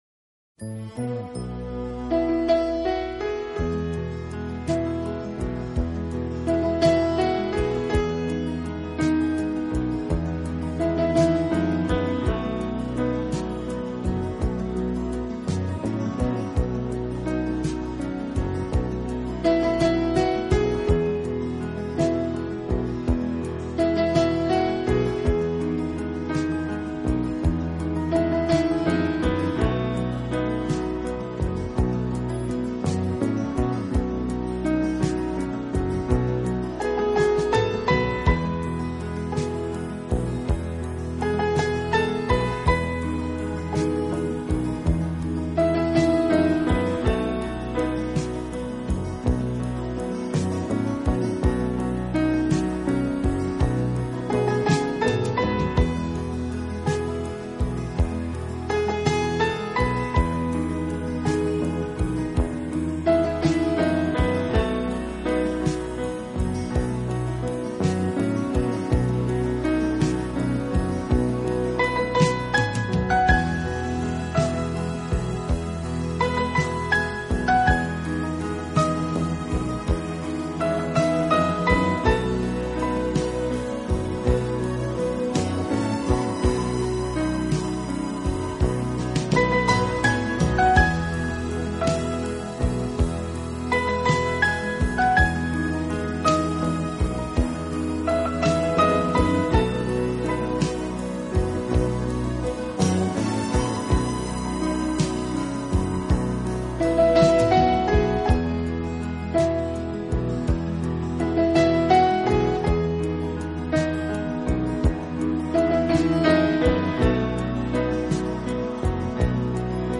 他以华丽的音色、优雅的旋律和俊美的形象，为自己确立了